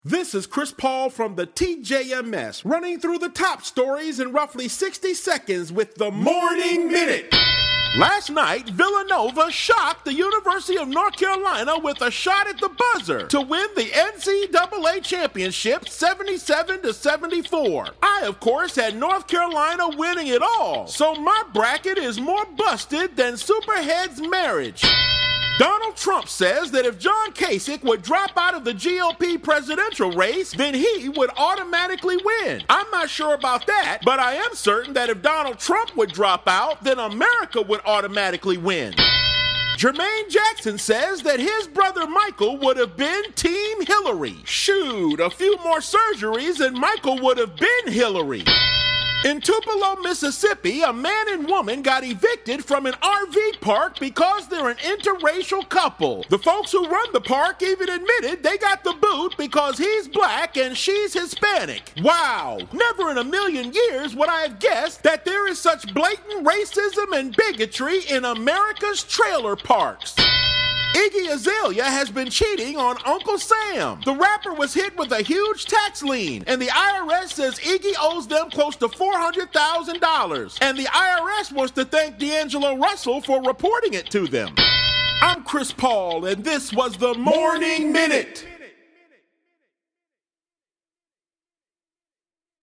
Comedian